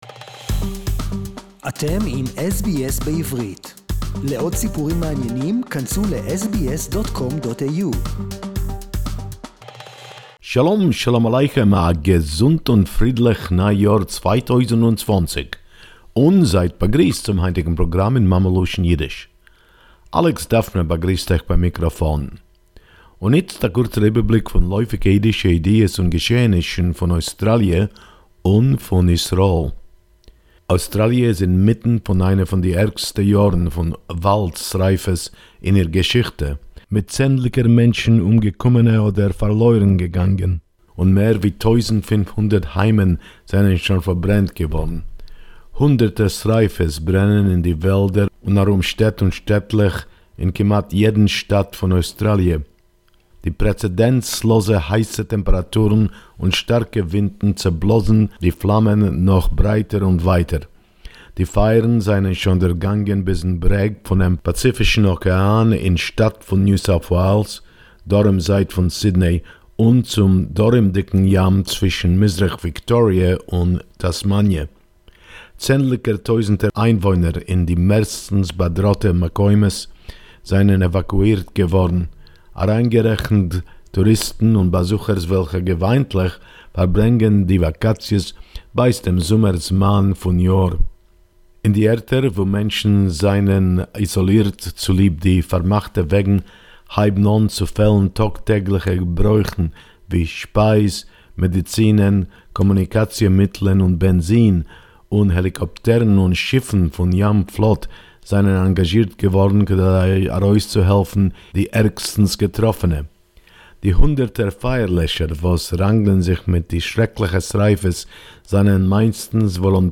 Yiddish report 5.1.2020 another anti-Semitic attack on Ultra Orthodox Jews in New York